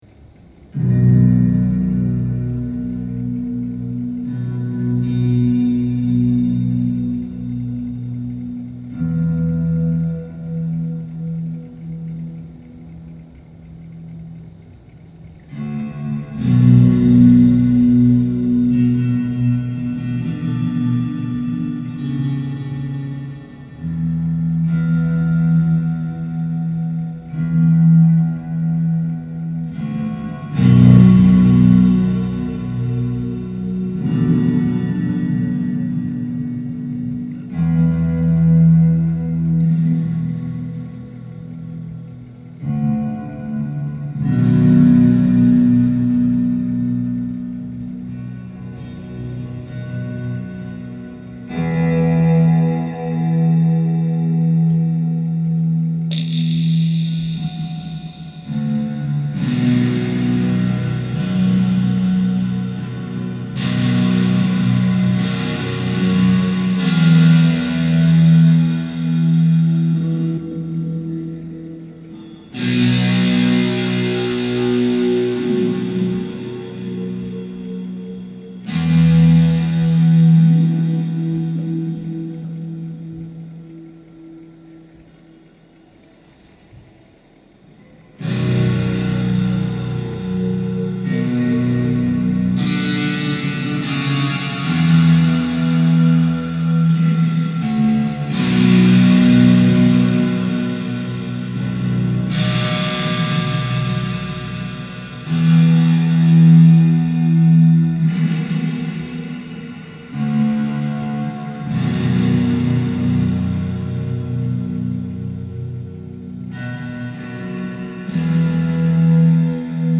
Soundtrack improvised and recorded